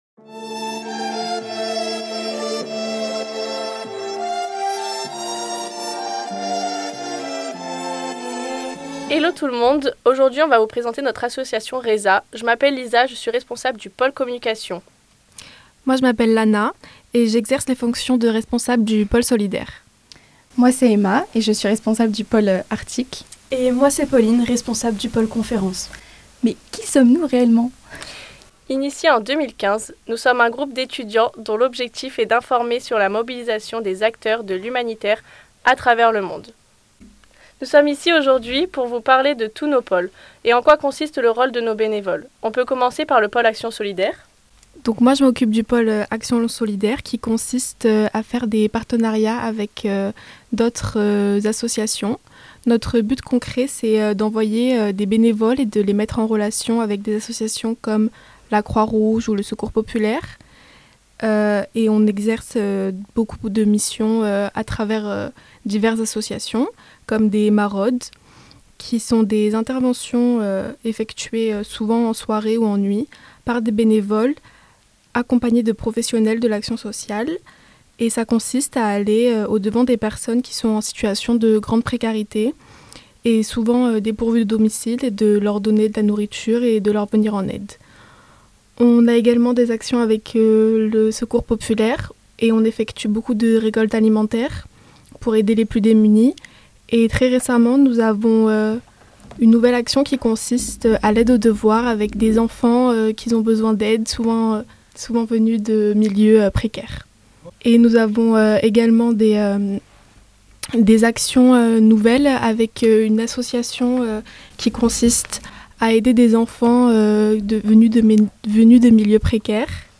L’engagement étudiant est au centre de cet épisode dans lequel les responsables de l’association Réesah, spécialisée dans l’action humanitaire, interviennent pour nous présenter leurs actions. Ces dernières sont organisées au sein de différents pôles, tels que le pôle actions solidaires, articles, conférences et communication.